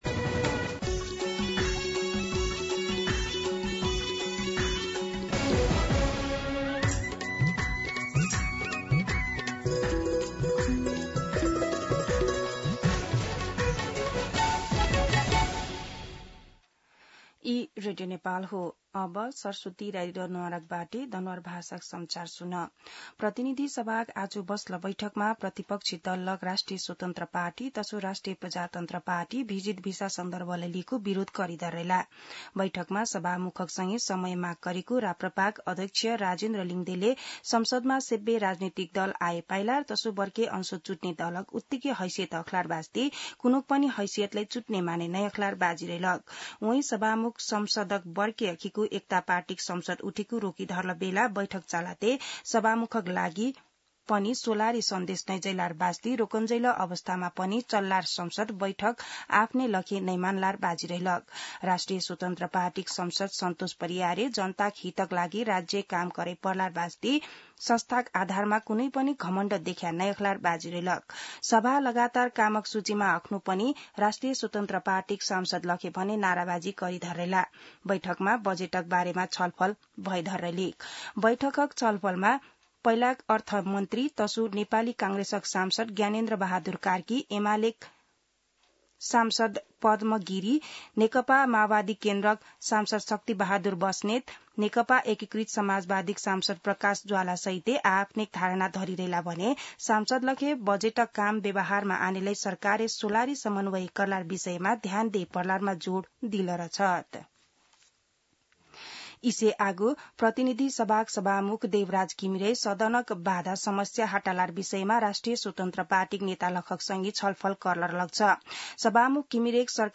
दनुवार भाषामा समाचार : २ असार , २०८२
Danuwar-News-3-2.mp3